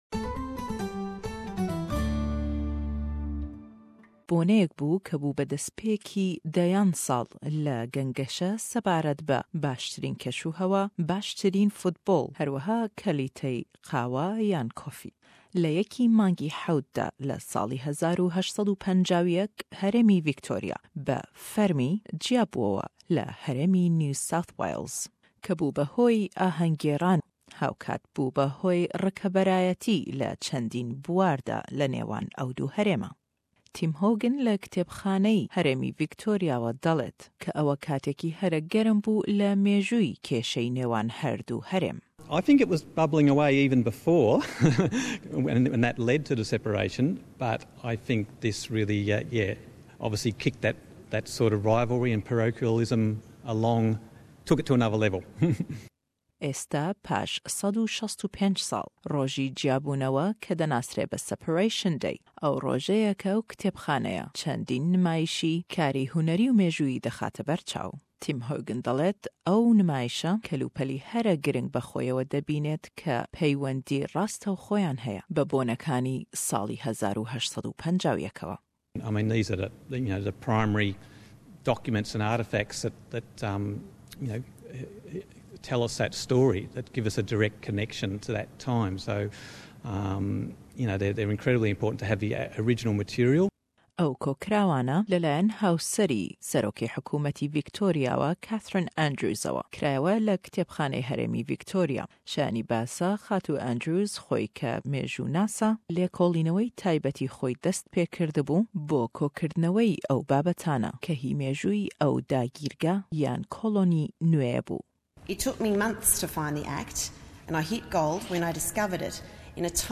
Le em mange da Herêmî Vîctorya 165 salley Brexît-î xoy yadkirdewe, ke ewysh cîya bûnewe û serbexo bûnî bû le kollonî NSW-î Brîtanî. Em raportey xware sebaret be webîrhênanewey ew boneye û nimashî kare hunerêkan ke bû be hoyî le dayik bûnî rîkeberayetî le nêwan Sharî Sydney û melborne